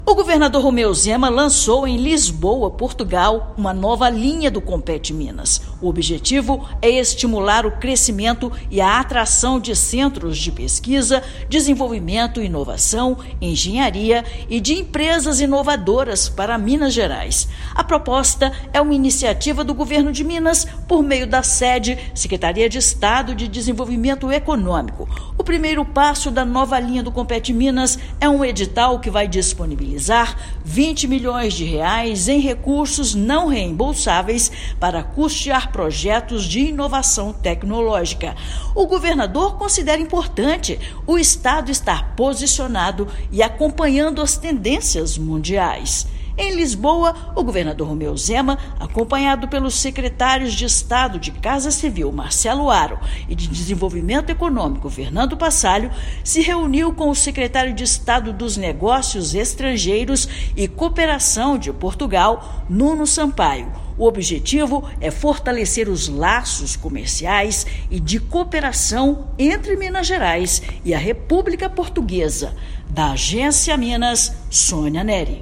Compete Minas - Linha Atração de Empresas Inovadoras (Come to Minas) vai disponibilizar R$ 20 milhões para fomentar instalação de centros de P&D e startups em território mineiro. Ouça matéria de rádio.